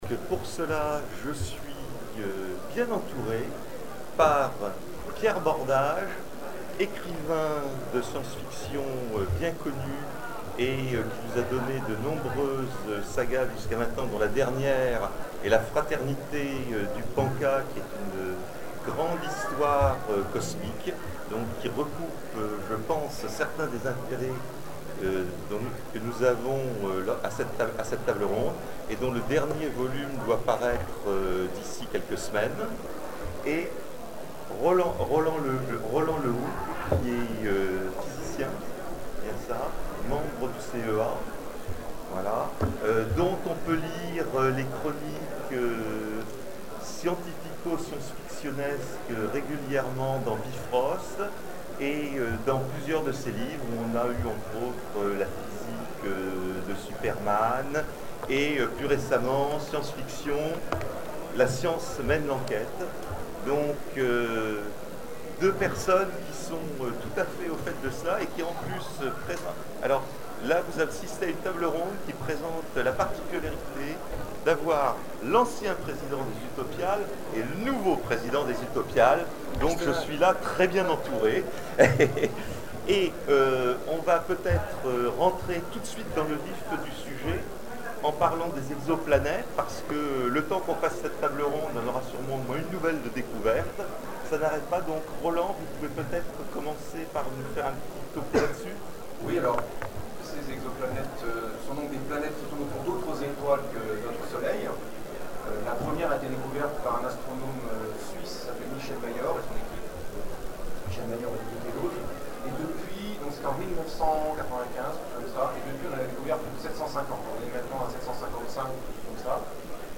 Zone Franche 2012 : Conférence La vie dans l’espace et le mystère de nos origines
(Attention, le son n'est pas très bon)